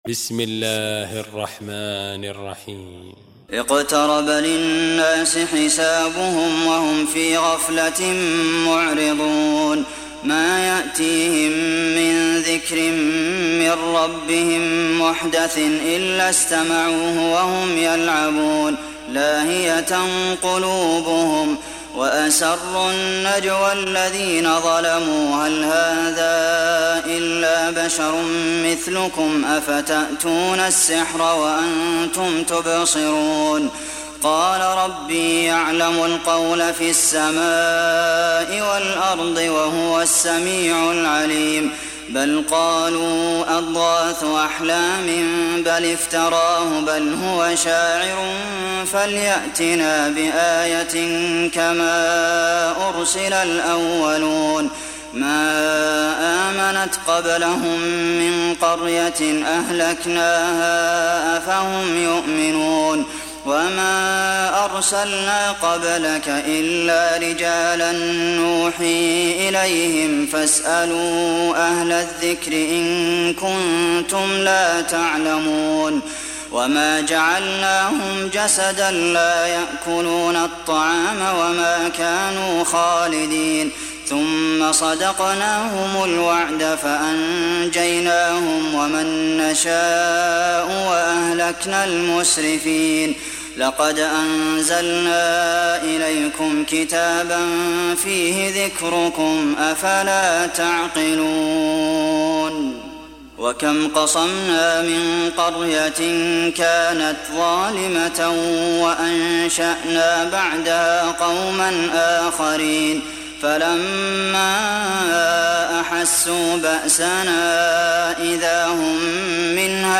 دانلود سوره الأنبياء mp3 عبد المحسن القاسم روایت حفص از عاصم, قرآن را دانلود کنید و گوش کن mp3 ، لینک مستقیم کامل